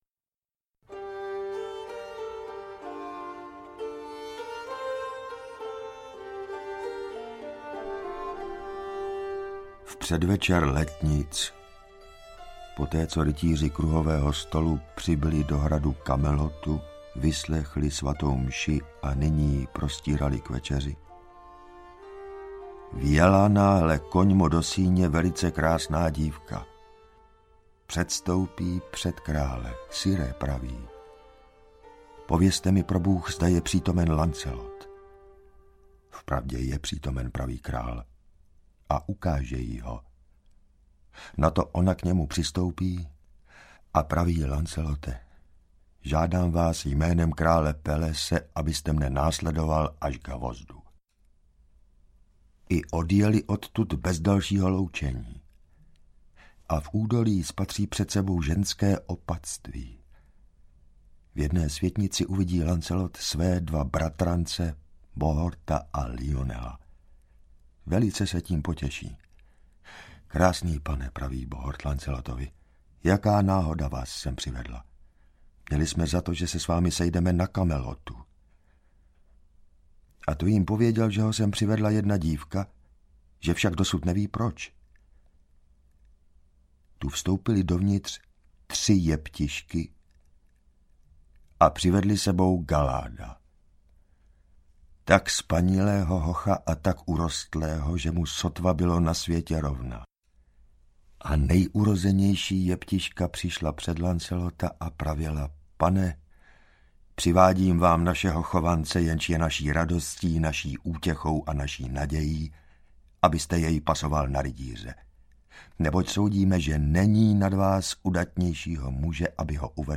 Ukázka z knihy
• InterpretJan Hartl